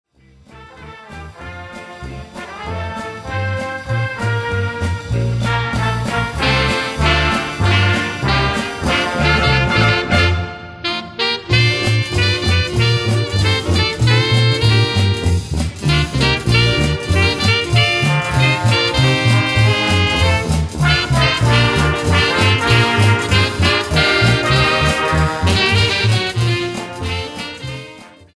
Orchesteraufnahmen
Orchesteraufnahmen Auch Orchester können wir mit unserer mobilen Aufnahmetechnik jederzeit an jedem Ort aufzeichnen. Ob in mehrspurigen Aufnahmen oder einfache Stereoaufnahme alles in digitaler Qualität. Feuerwehr Orchester Essen Hörprobe